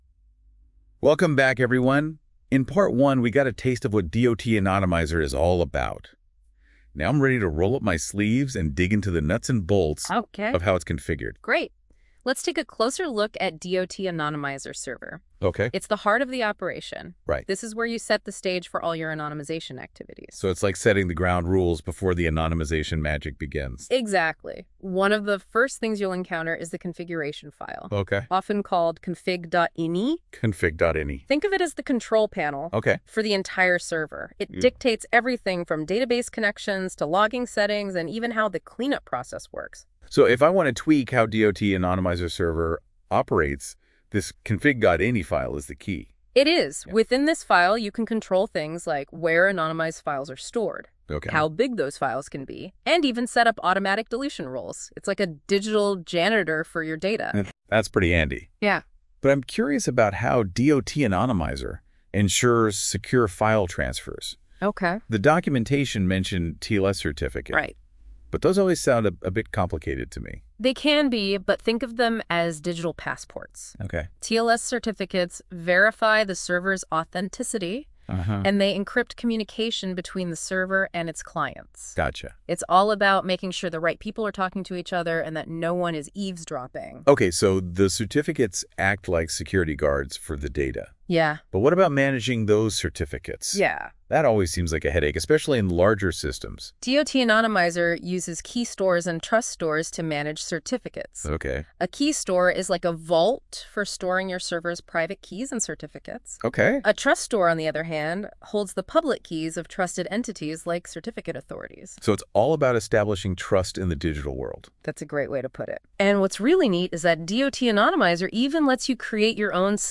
In this podcast, hosted by AI, our two generated speakers take you on a complete tour of DOT Anonymizer, a data anonymization tool.